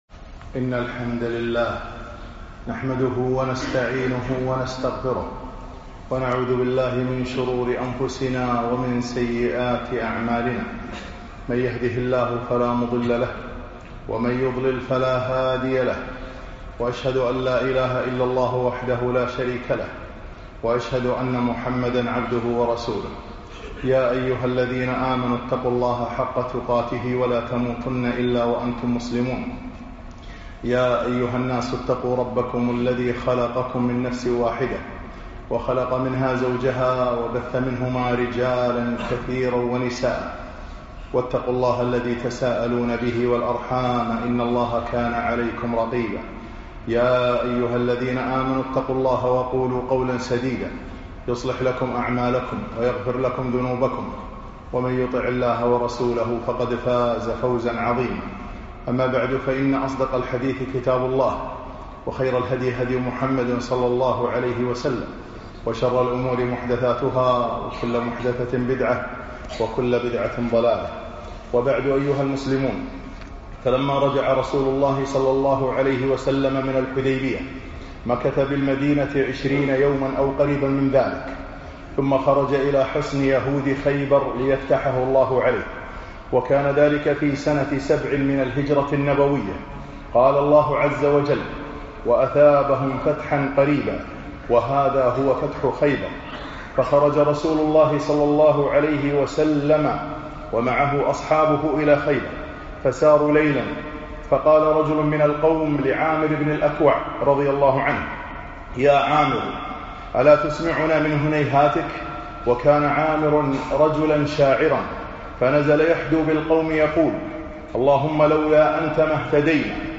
خطب السيرة النبوية 21